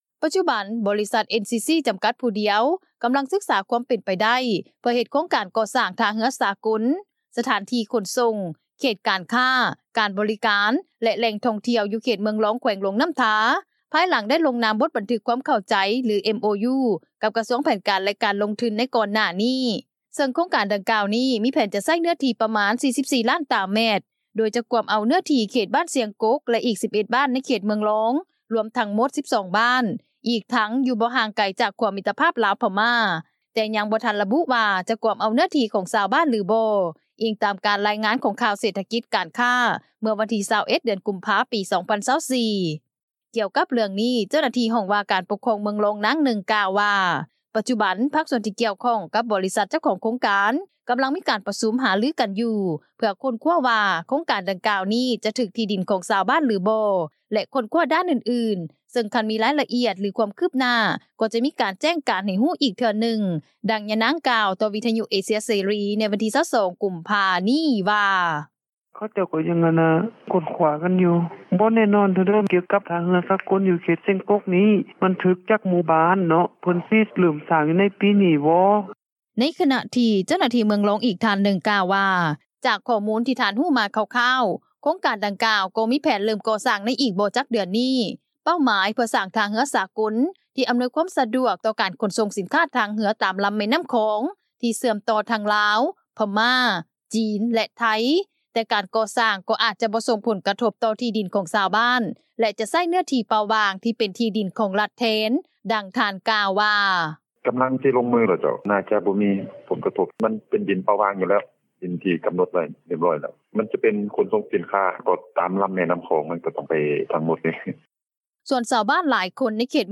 ດັ່ງຍານາງ ກ່າວ ຕໍ່ວິທຍຸເອເຊັຽເສຣີ ໃນວັນທີ 22 ກຸມພາ ນີ້ວ່າ:
ດັ່ງຊາວບ້ານ ທ່ານນຶ່ງ ກ່າວວ່າ:
ດັ່ງຊາວບ້ານ ອີກທ່ານນຶ່ງກ່າວວ່າ: